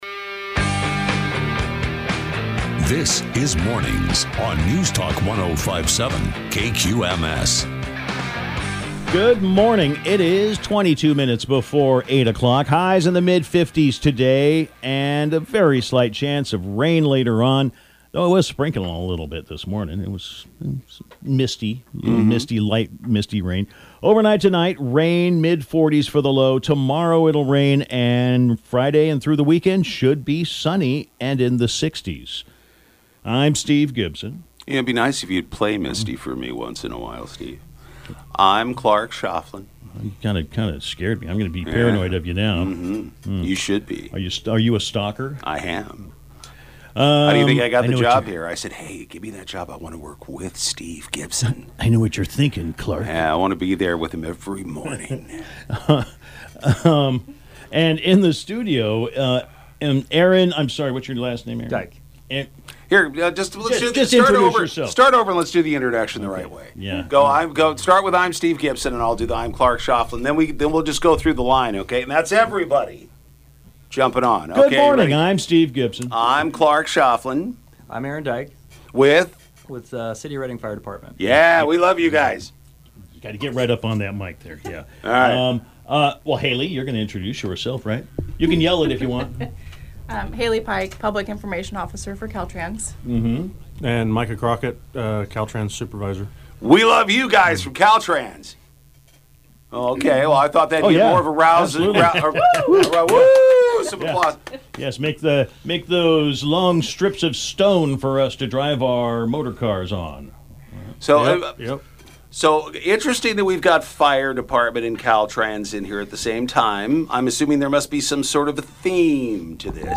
Listen to the interview here to hear their message firsthand and help protect those who protect you.